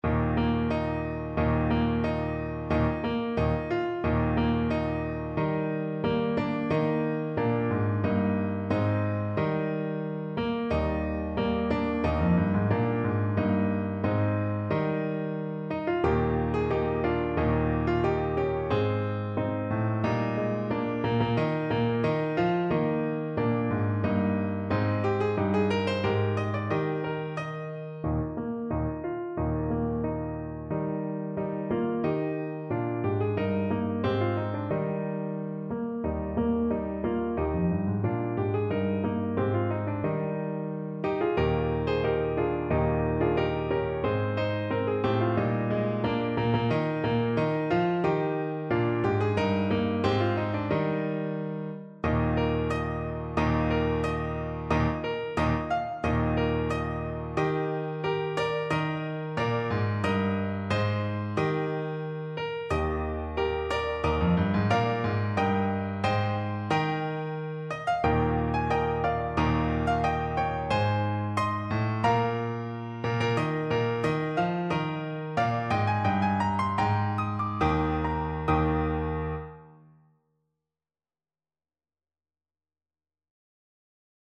Play (or use space bar on your keyboard) Pause Music Playalong - Piano Accompaniment Playalong Band Accompaniment not yet available transpose reset tempo print settings full screen
Trombone
2/2 (View more 2/2 Music)
Eb major (Sounding Pitch) (View more Eb major Music for Trombone )
March ( = c. 90)